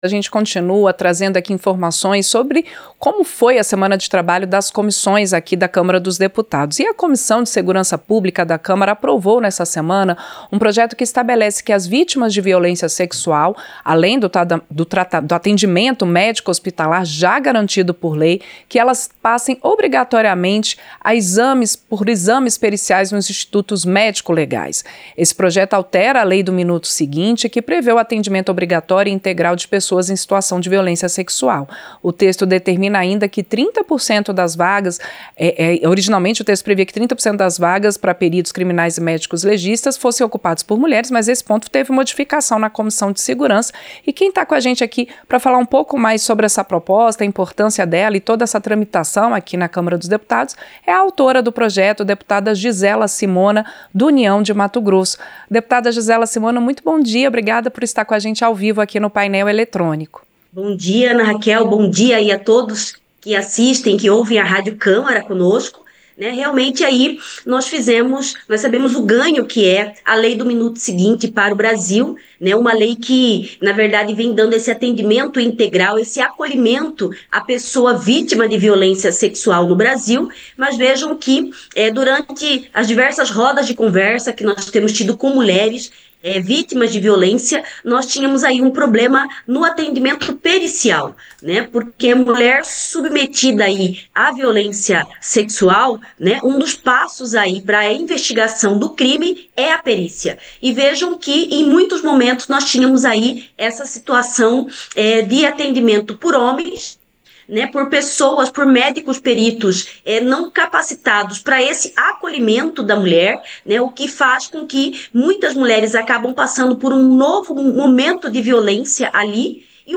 Entrevista - Dep. Gisela Simona (União-MT)